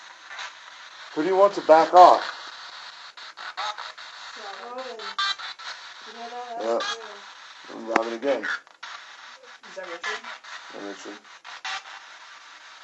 Spirit box says “bend over”